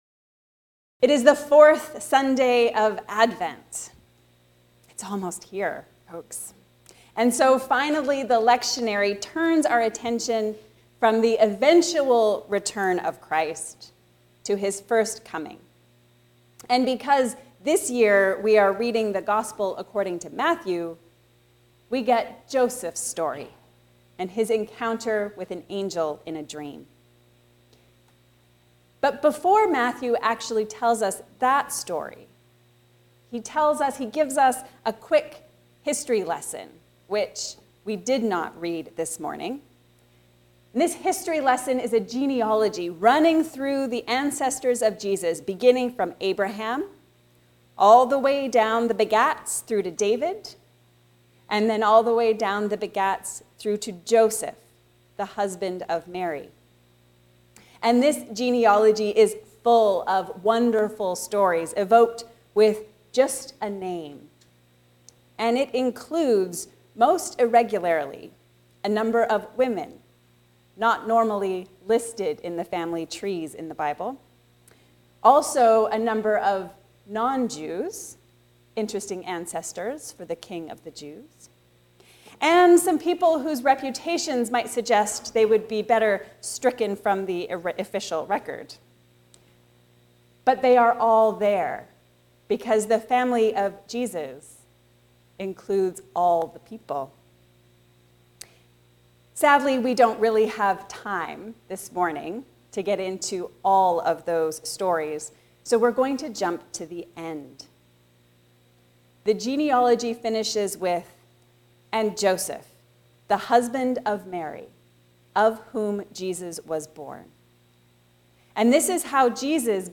The Holy Family is Chosen Family. A sermon on Matthew 1:18-25